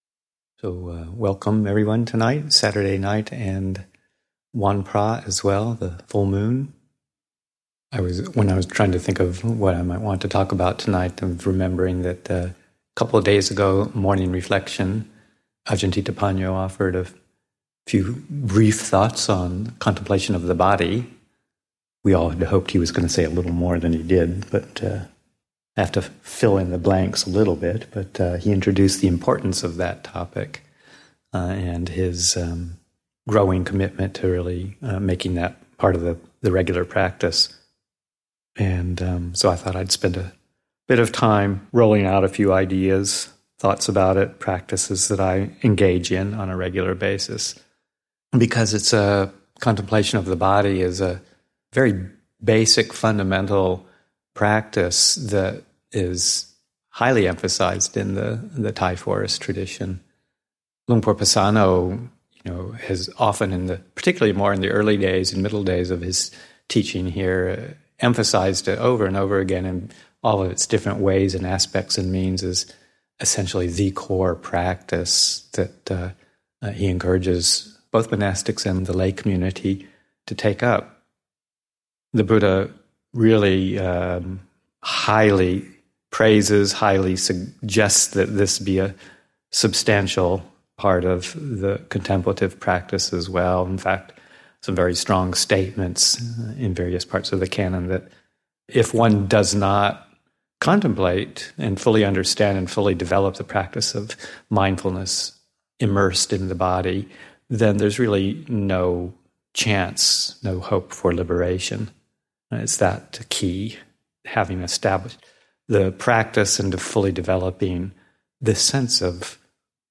Dhamma Talks given at Abhayagiri Buddhist Monastery.